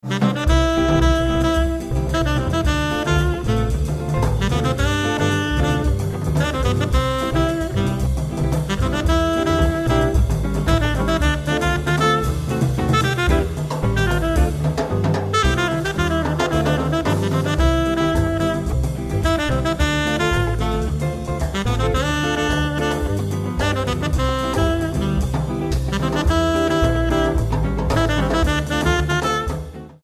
Live at Airegin on DEC. 9, 2003